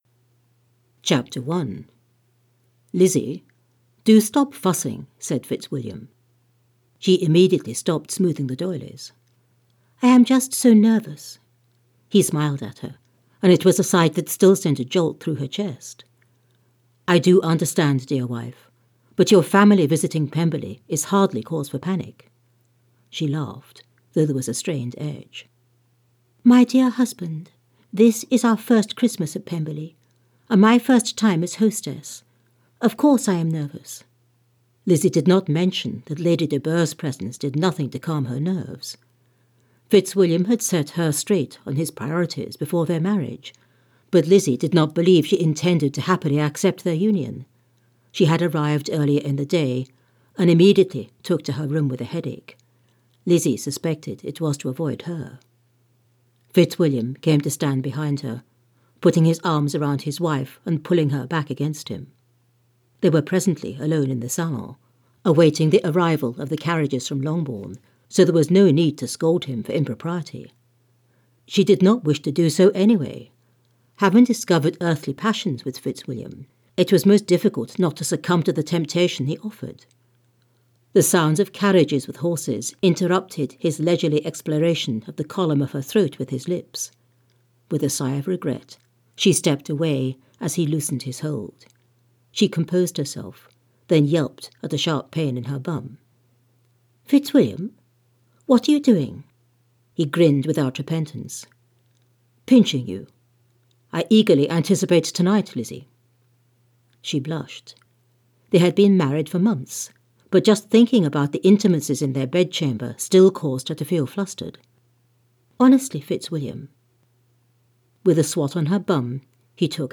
The Christmas at Pemberley audiobook is available on Amazon, Audible and iTunes.